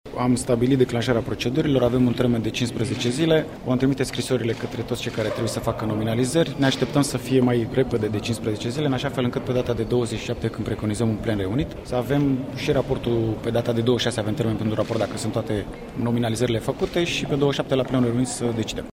Solicitarea a fost formulată de PNL. Vicepreşedintele Senatului, Claudiu Manda: